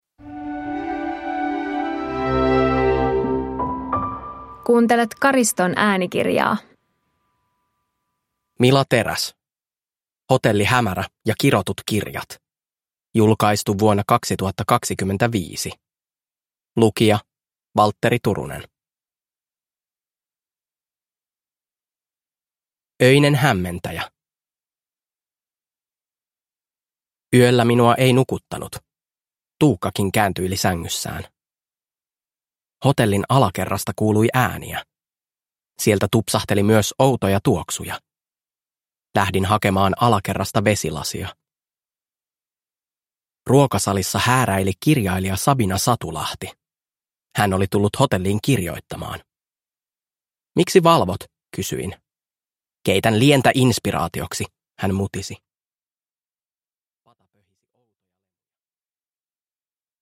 Hotelli Hämärä ja kirotut kirjat – Ljudbok